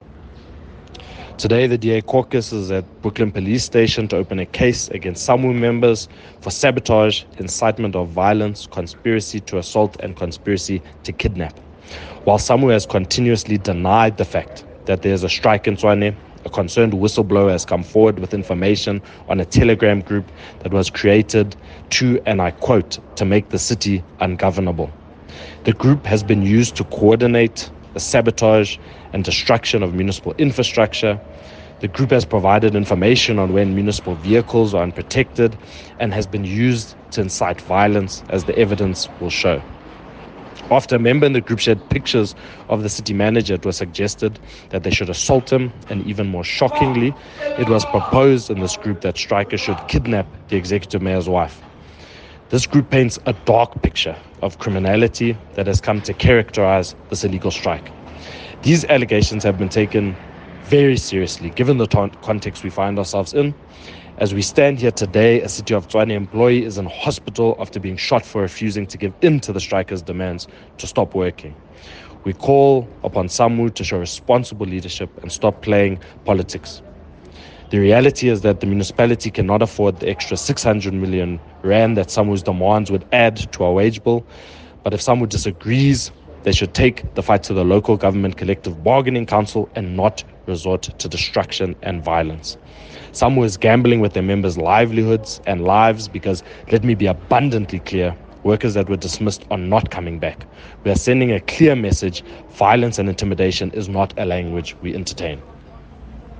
Issued by Cllr Kwena Moloto – DA Tshwane Caucus Spokesperson
Note To Editors: Please find photos here, English soundbite